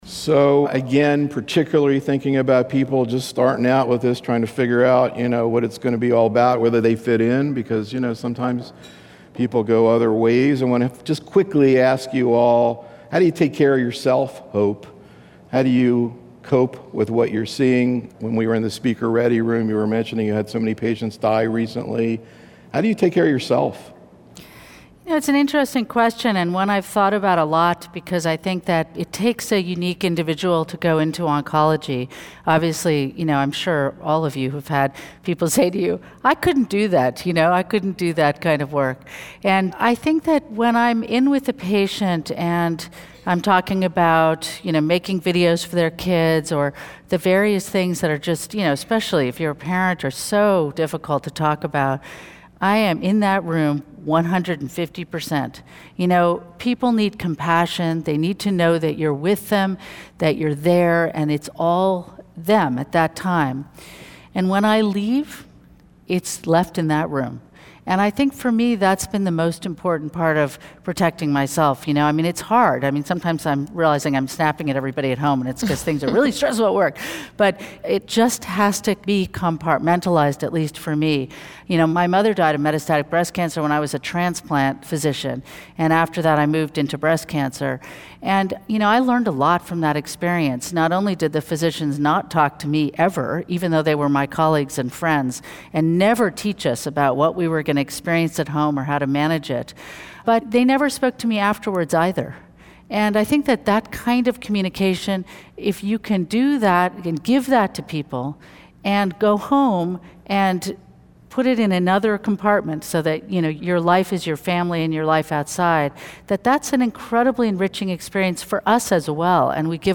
In these audio proceedings from a symposium held in conjunction with the Oncology Nursing Society's 2012 Annual Congress, the invited oncology nursing professionals participating as part of our faculty panel present actual patient cases from their practices, setting the stage for faculty discussion of optimal therapeutic and supportive care strategies in breast cancer.